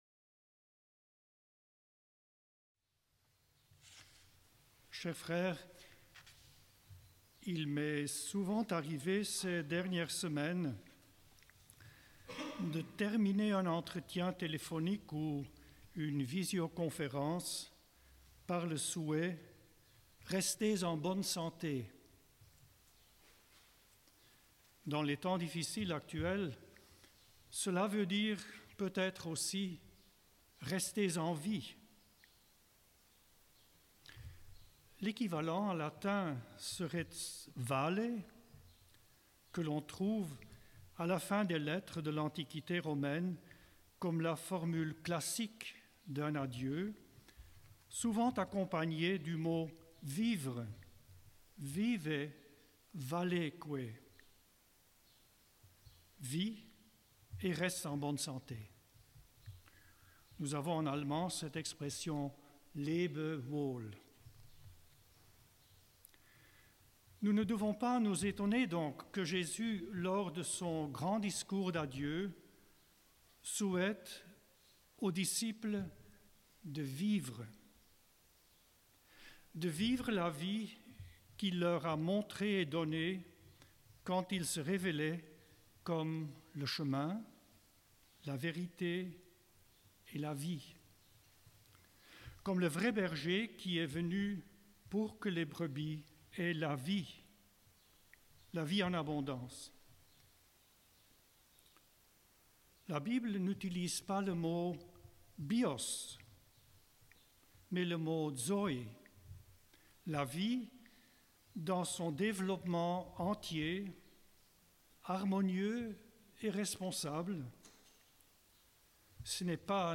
Enregistrement : l'homélie